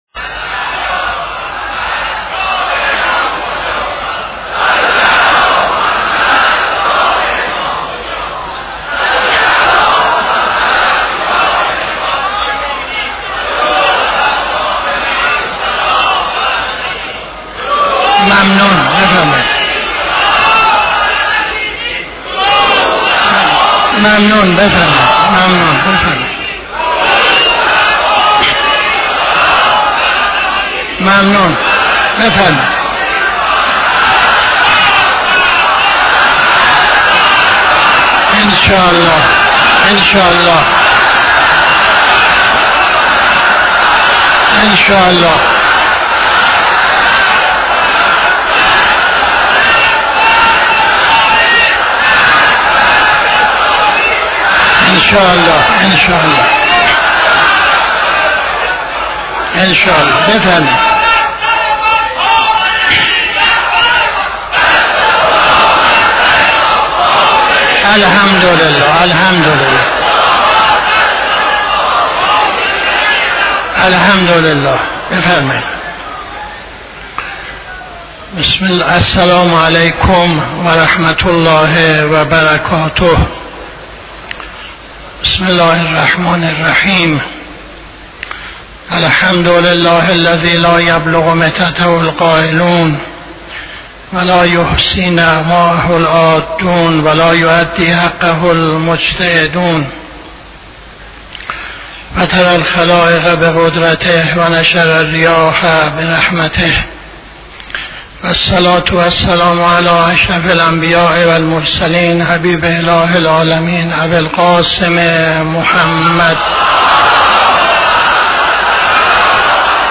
خطبه اول نماز جمعه 17-12-80